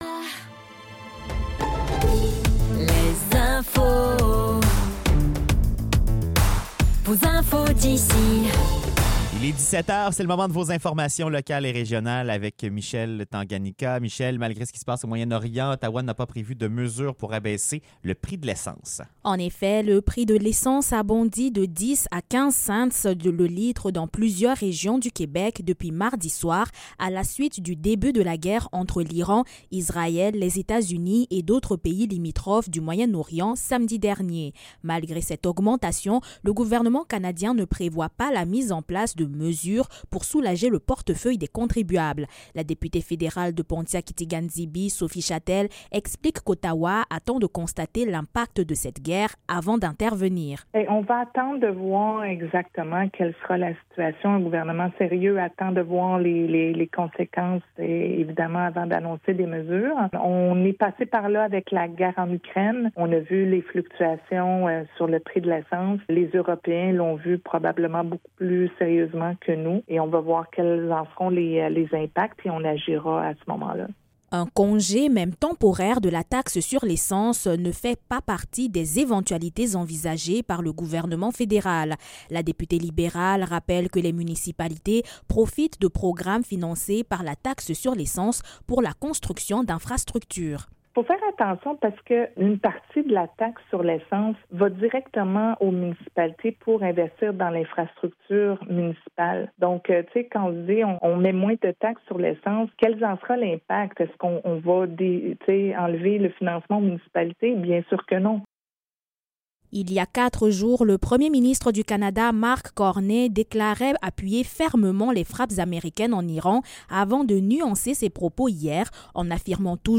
Nouvelles locales - 4 mars 2026 - 17 h